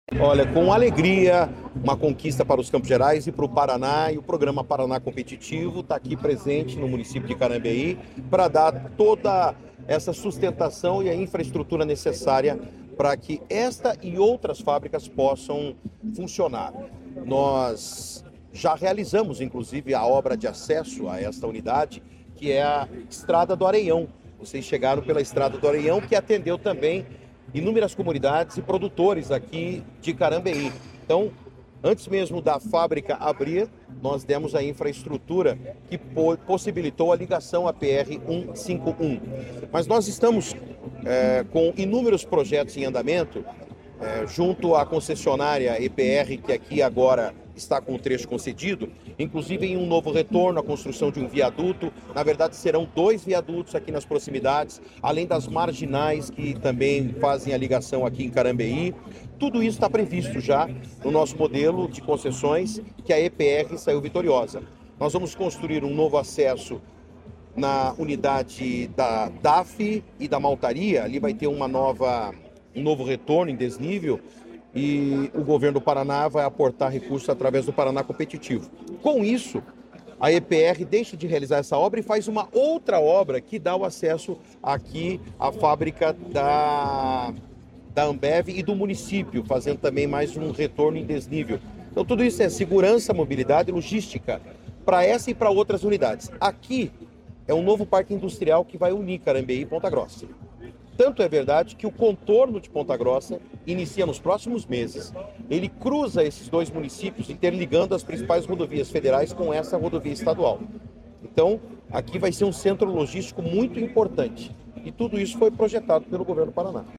Sonora do secretário de Infraestrutura e Logística, Sandro Alex, sobre a inauguração da nova fábrica de garrafas de vidro da Ambev em Carambeí